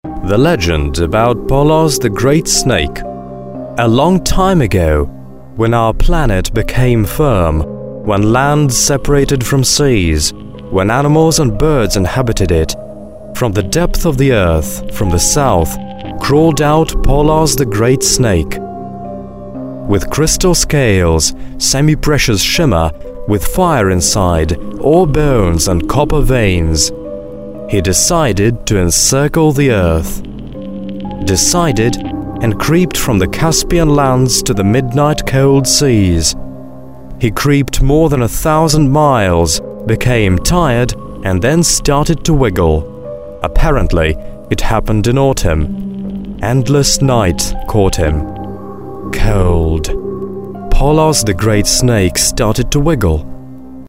mystОзвучивание текста на английском языке (043)